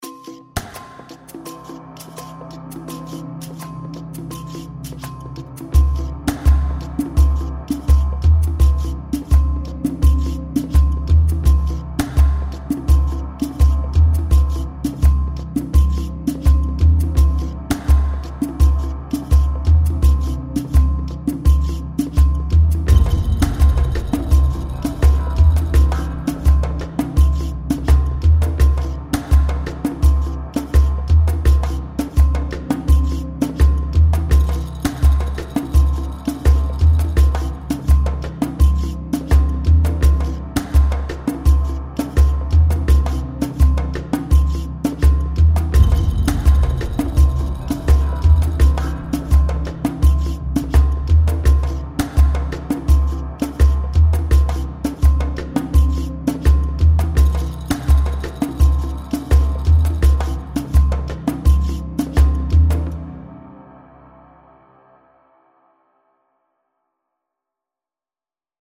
suspense - percussions